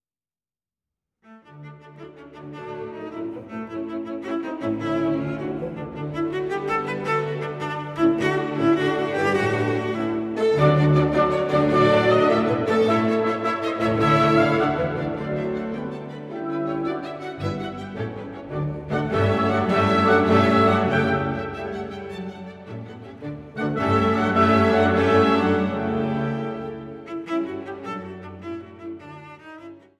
Violoncello